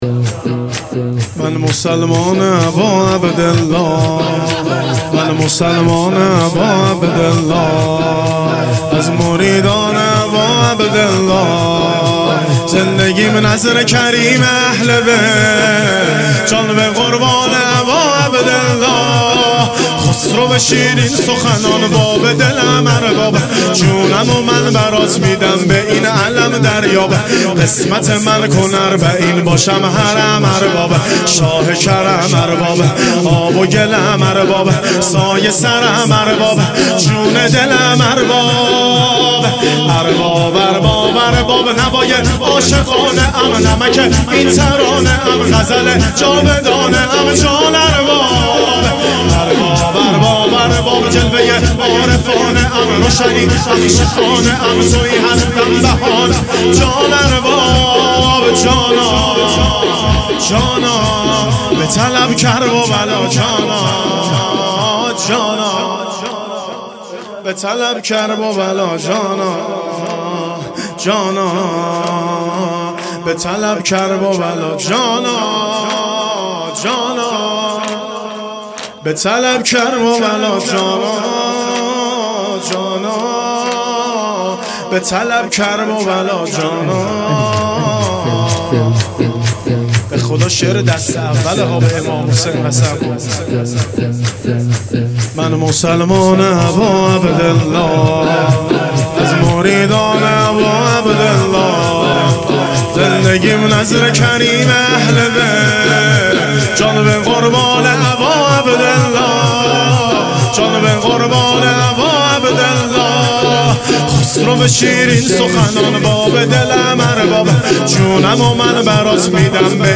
شب پنجم محرم 1400
شور